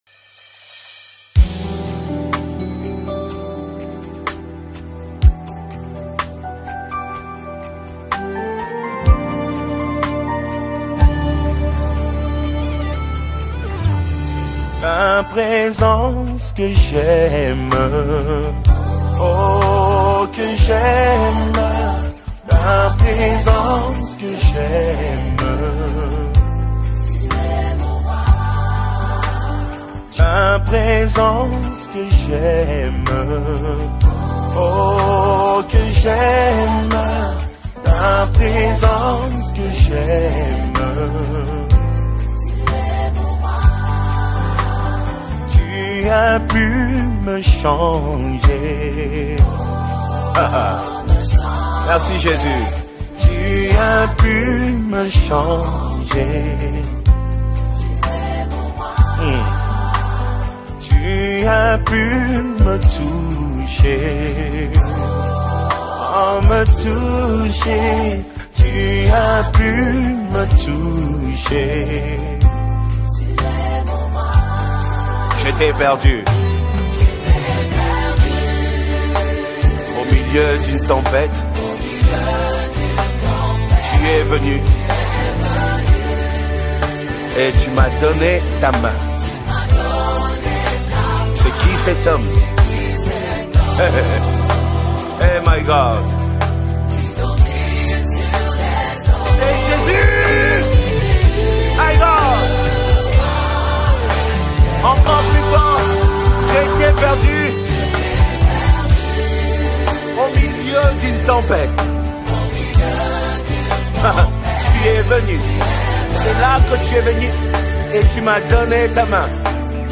a powerful worship anthem